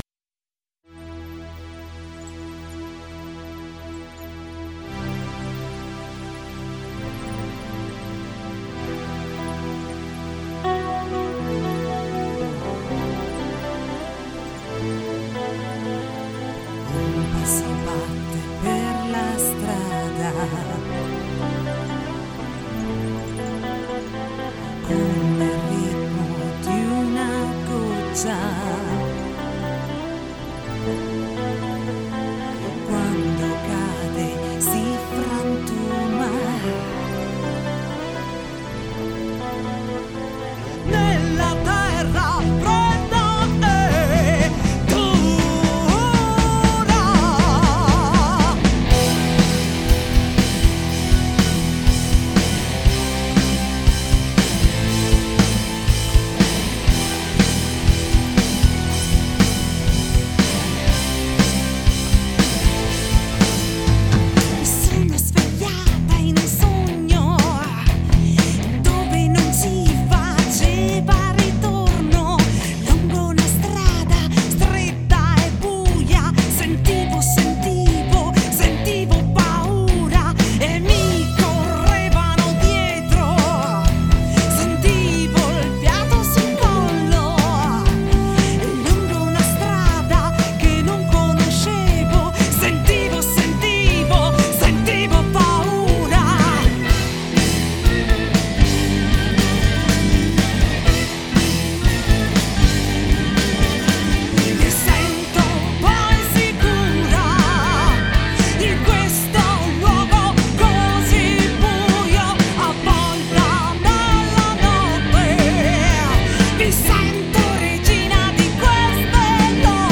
Formazione veronese di 5 elementi
prog rock moderno e la new wave italiana